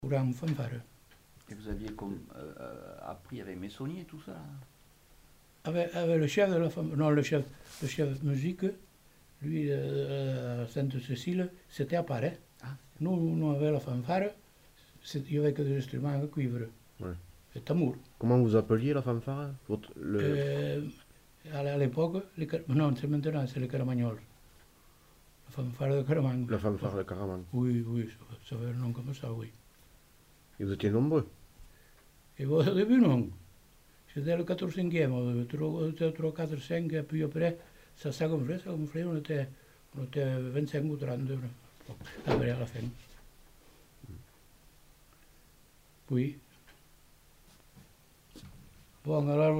Genre : récit de vie